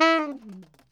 TNR SHFL E4.wav